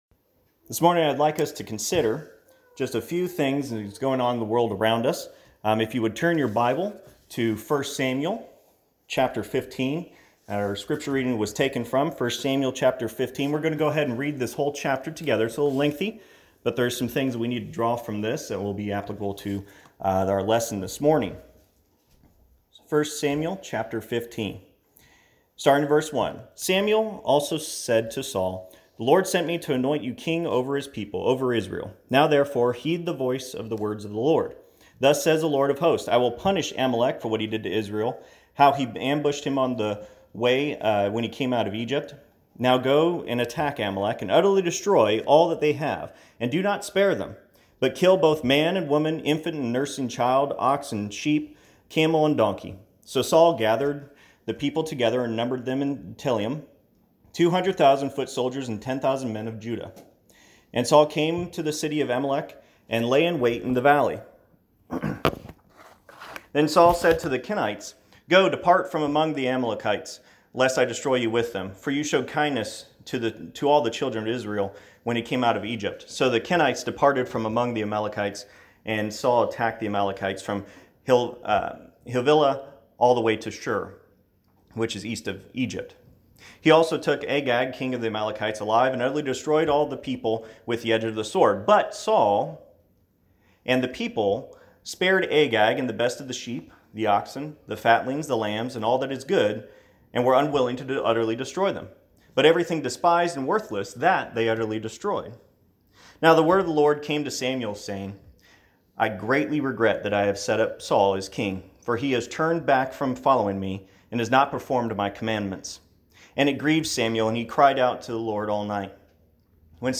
1 Samuel 15:22-23 Service Type: Sunday AM You may be wondering